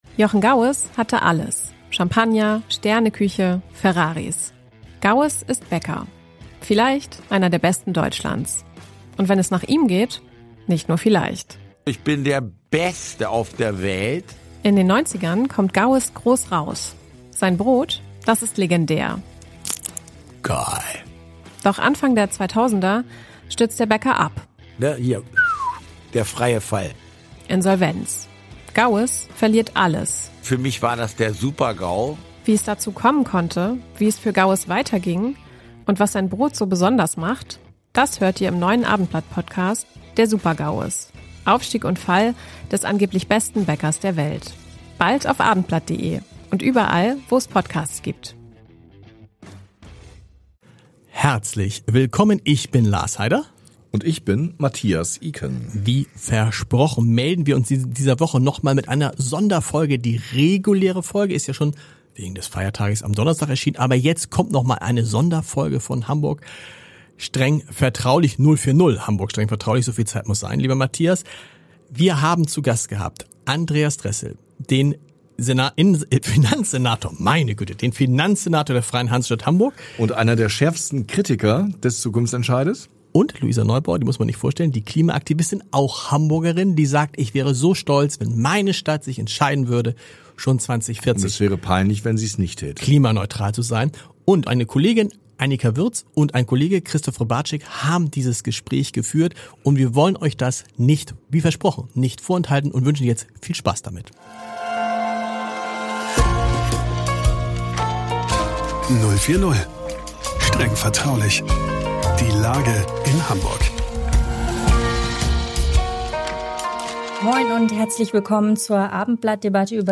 Luisa Neubauer kämpft für das schärfere Ziel und sieht darin die Chance auf eine lebenswerte Stadt. Andreas Dressel (SPD) warnt hingegen vor unbezahlbaren Kosten für Bürger und Wirtschaft. Hören Sie die hitzige Auseinandersetzung über die Dringlichkeit der Klimakrise, die Frage der sozialen Gerechtigkeit bei Sanierungskosten und wie viel Ehrlichkeit die Politik den Hamburgerinnen und Hamburgern schuldig ist.